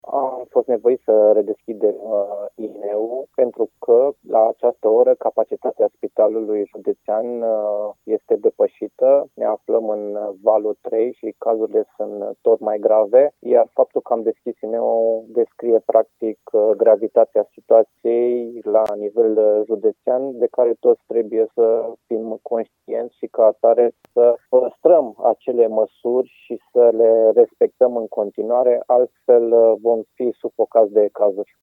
Directorul DSP Arad, dr. Horea Timiș: